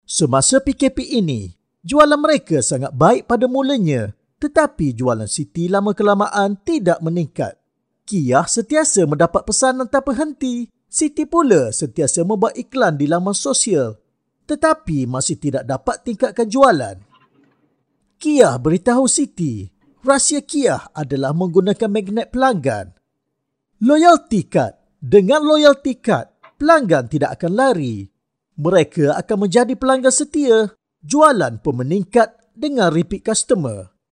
马来西亚语男声 讲述 故事 干音
沉稳大气 活力